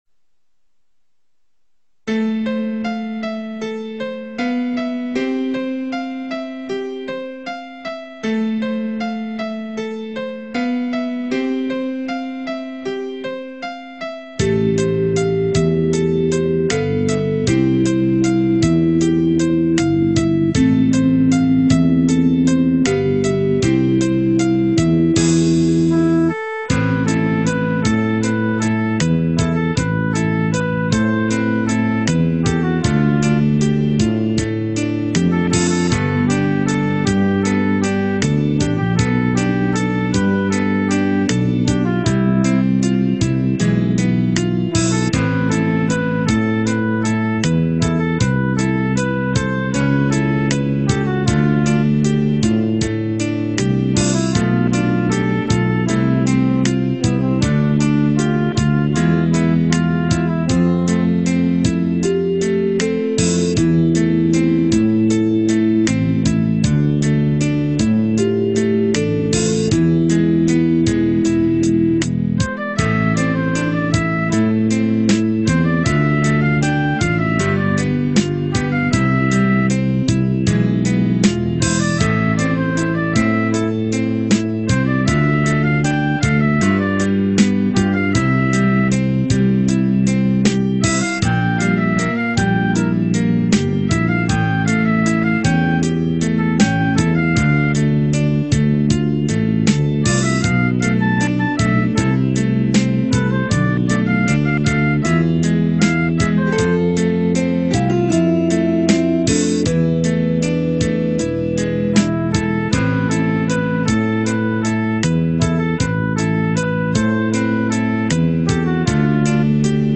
Вот еще, если нужно, караоке-версия.